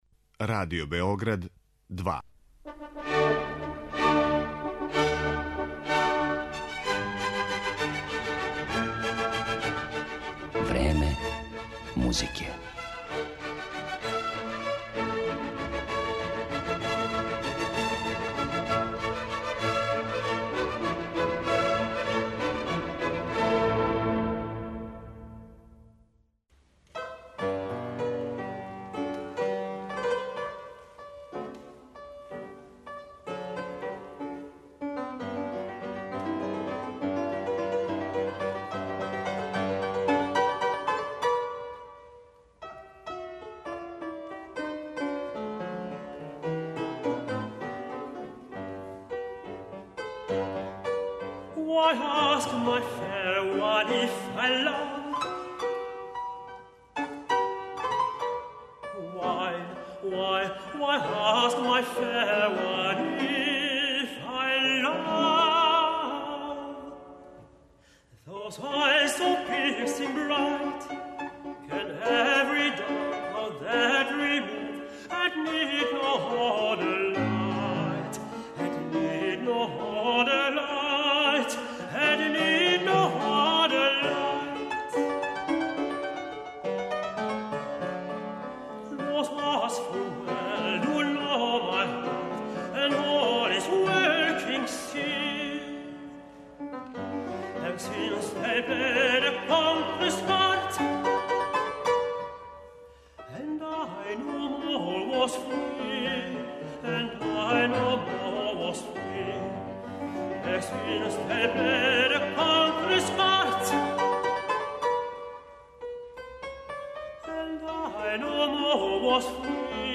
Многима је податак да је Јозеф Хајдн готово читавог живота писао песме за глас и клавир сасвим непознат, јер се овај не тако мали део његове заоставштине и данас ретко проналази на концертним програмима и снимцима.
белгијски тенор
бугарског пијанисту